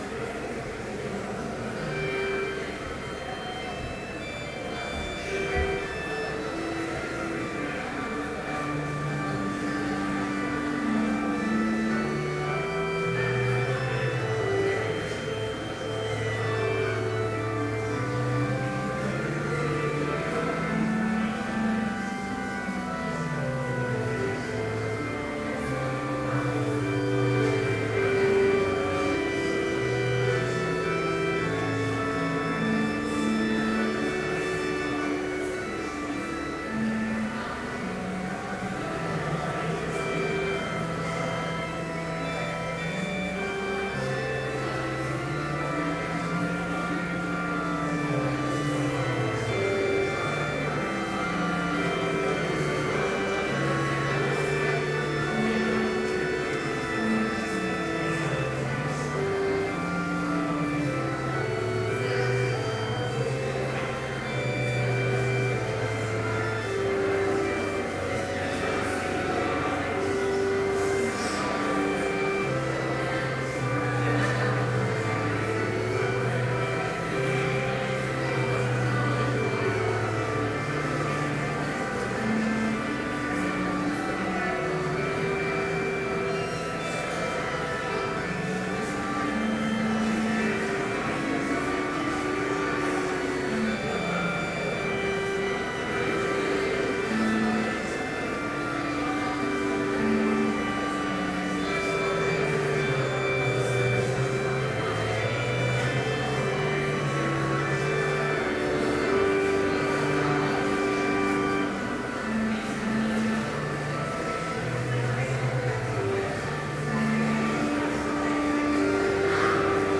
Complete service audio for Chapel - September 12, 2012